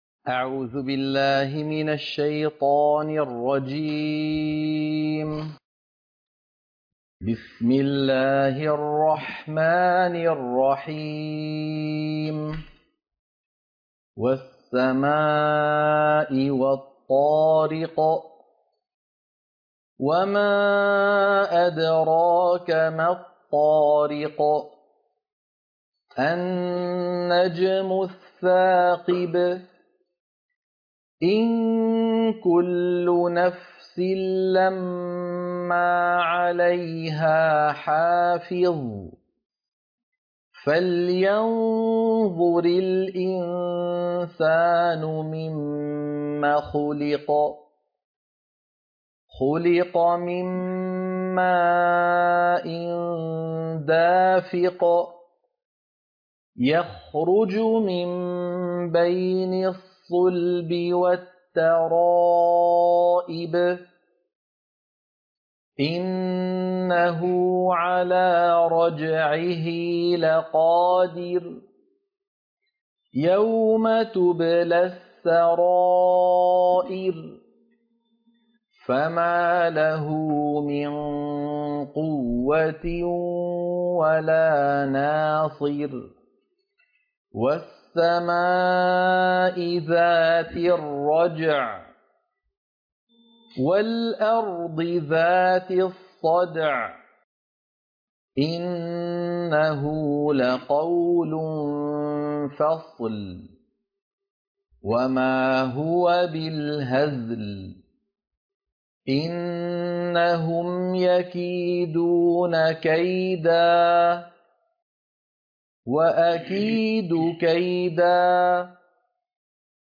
عنوان المادة سورة الطارق - القراءة المنهجية